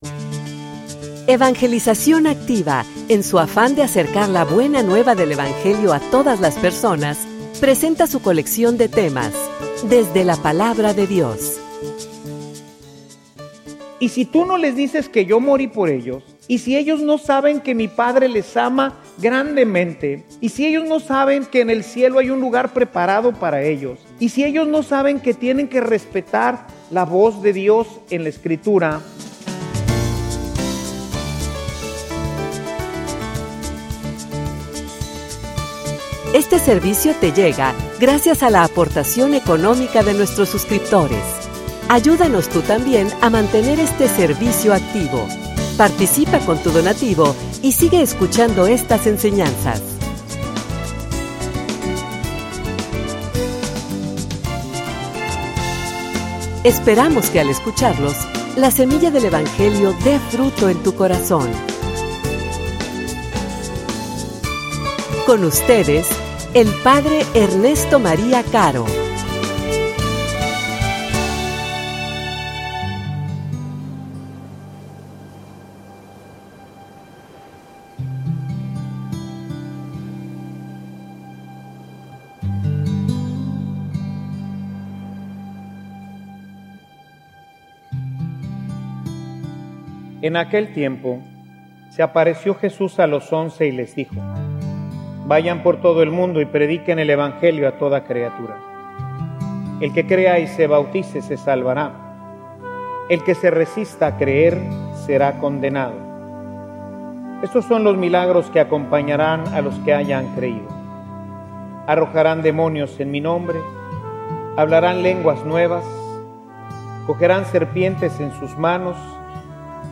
homilia_No_le_fallemos_al_Senor.mp3